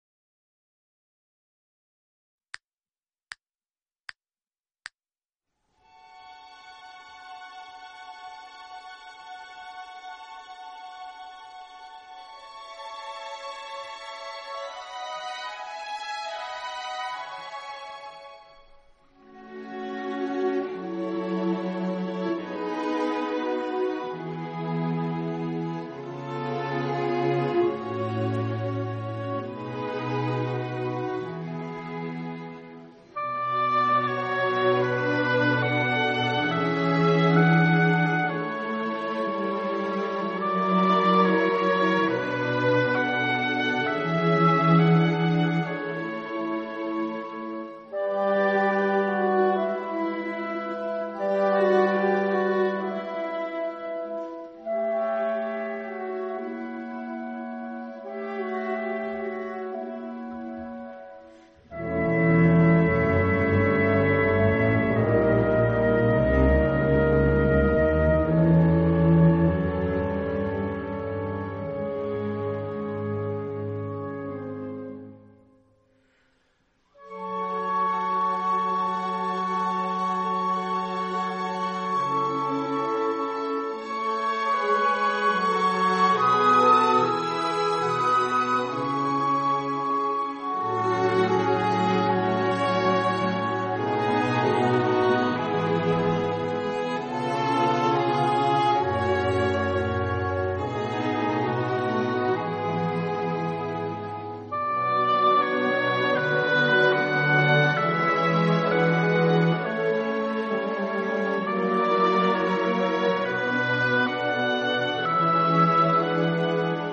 Music only Traditional Classical Music For Kids